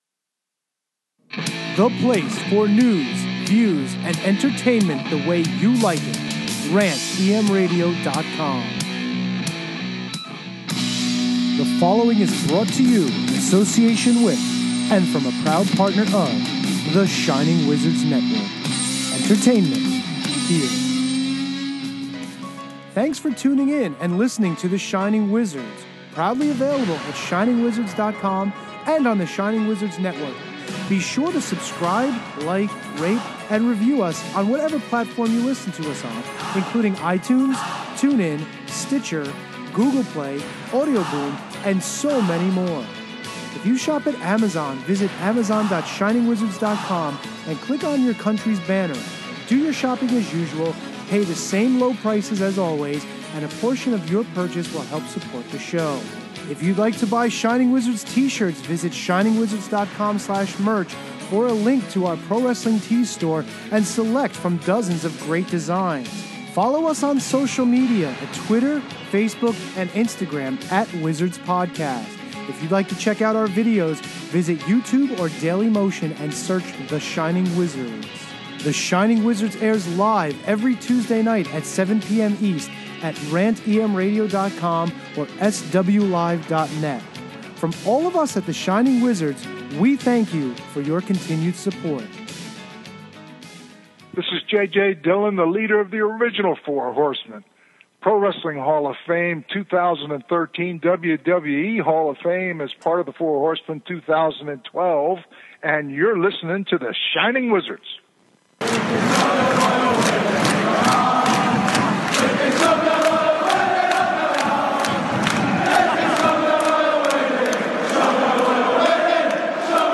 The guys hit up Studio A, and the big news is lots of money.